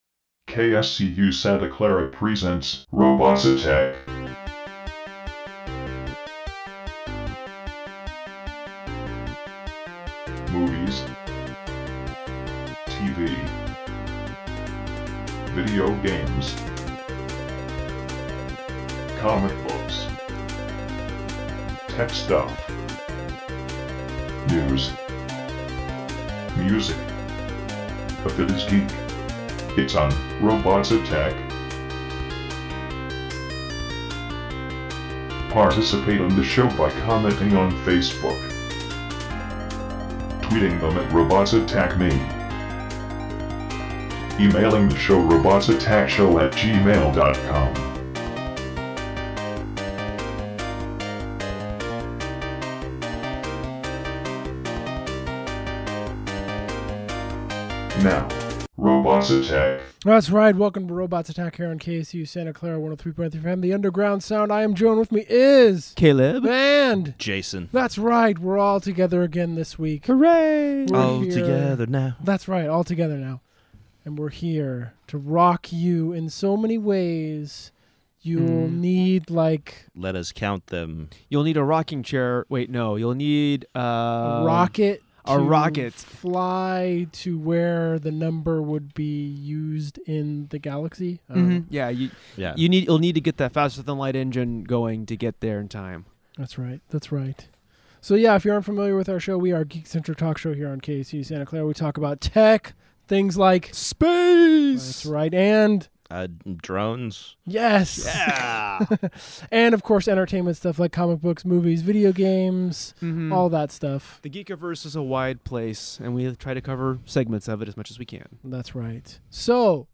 Geek Talk